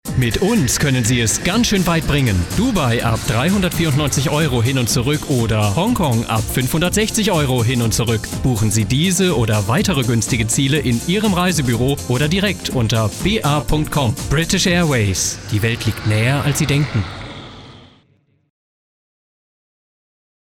Deutscher Sprecher, Literaturlesungen, Kulturmoderation, Bariton
Sprechprobe: Sonstiges (Muttersprache):
Native German narrator for literature, readings, and presentation of cultural programmes